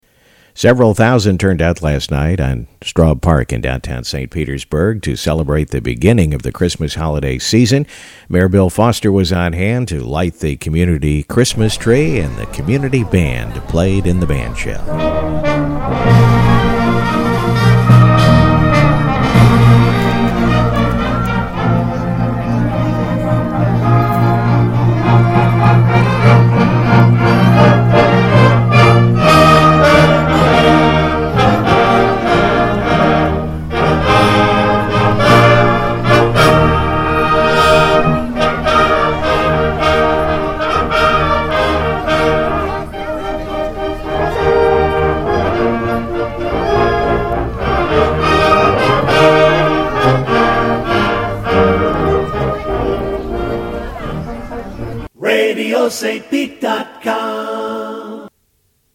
St. Petersburg Community Band at Straub Park Tree Lighting 11/29/13